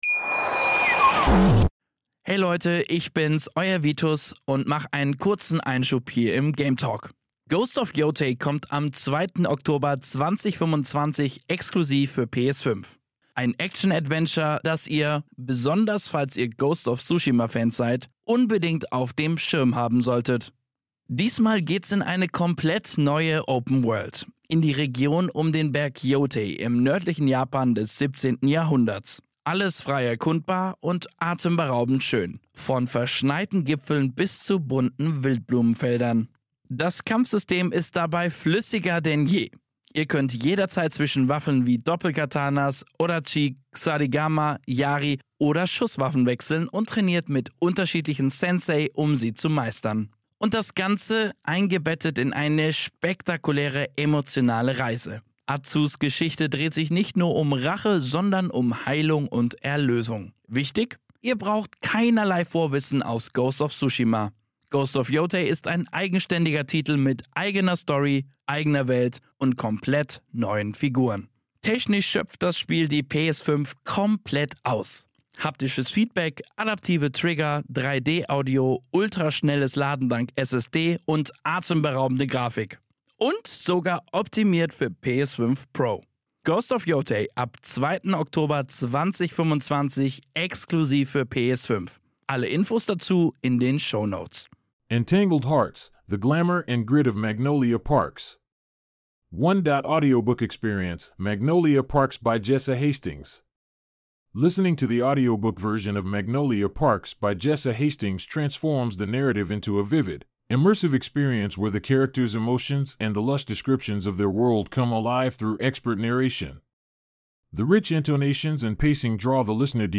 1.有声读物体验：杰萨·黑斯廷斯的《木兰公园》
书中人物的情感和对世界华丽的描述，通过专业的旁白跃然纸上。丰富的语调和节奏将听众带入人物关系的复杂之中，使对话和紧张气氛的微妙之处产生共鸣，而这种共鸣是印刷文本无法捕捉到的。